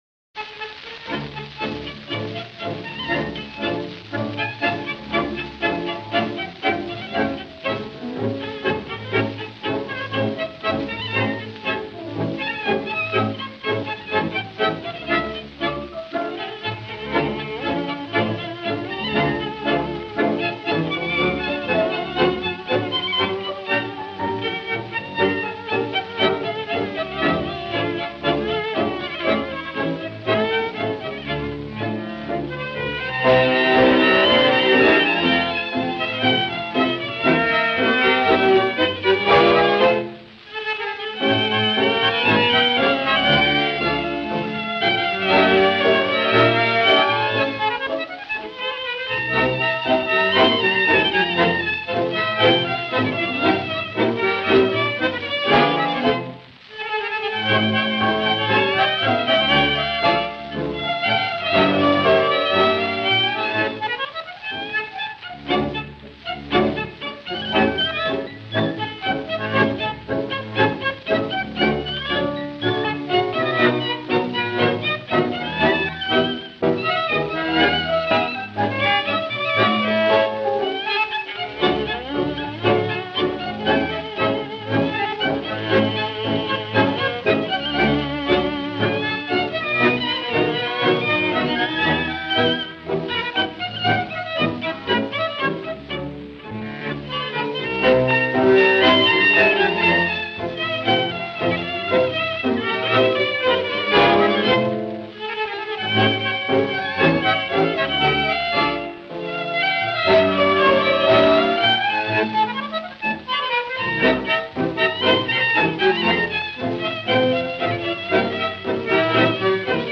Voici un enregistrement audio de 1929.